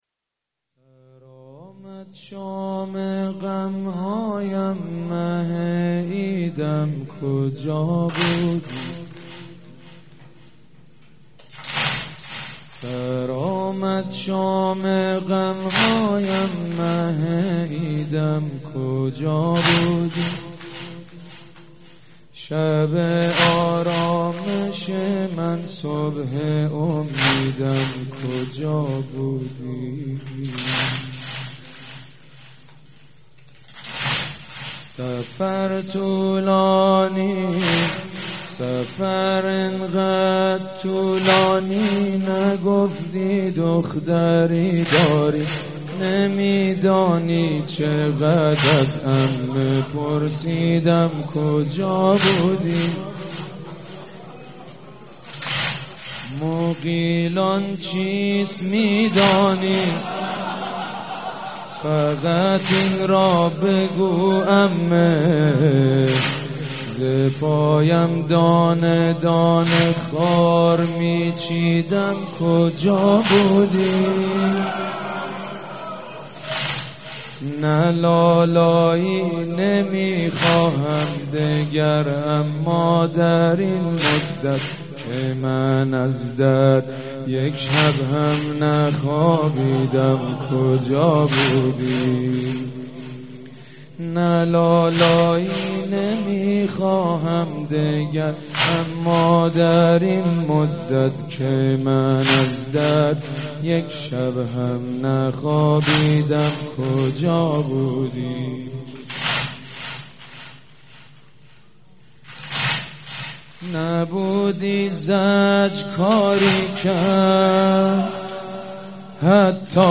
صوت مراسم شب سوم محرم ۱۴۳۷هیئت ریحانه الحسین(ع) ذیلاً می‌آید: